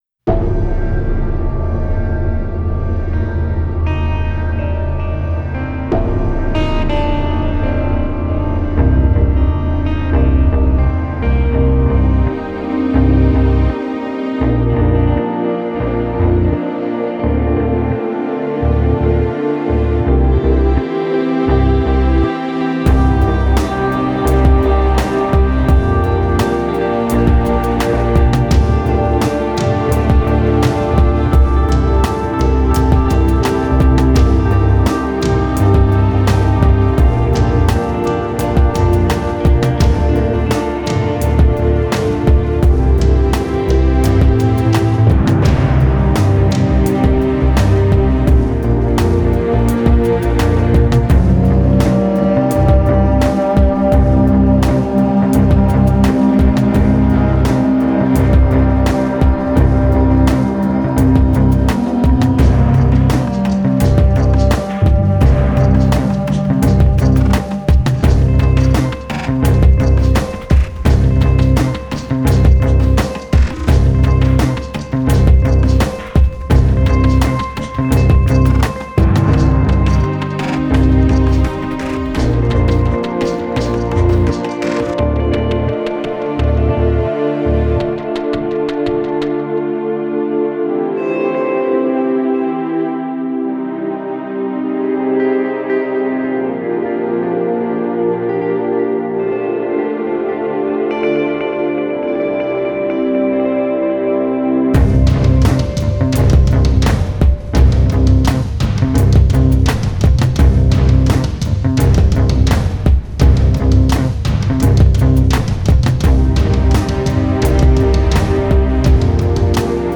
Original Music Soundtrack